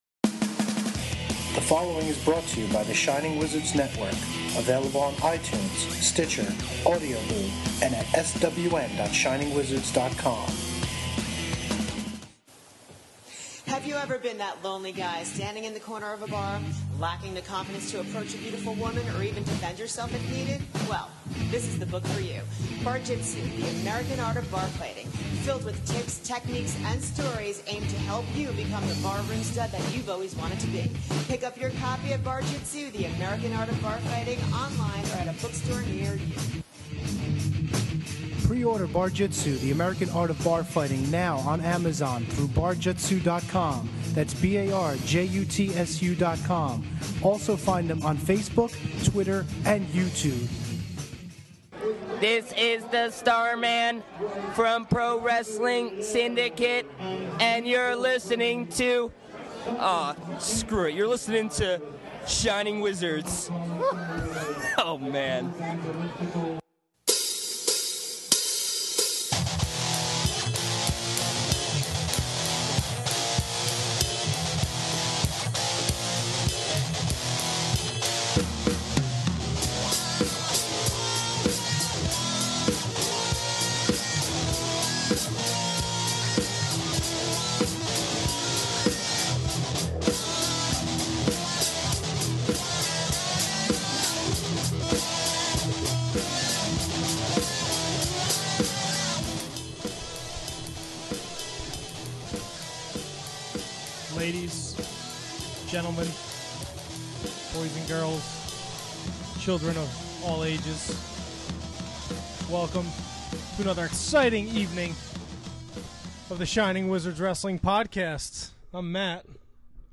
They also do some singing, play with a new toy, and, unfortunately, take a harsh look at the current state of WWE.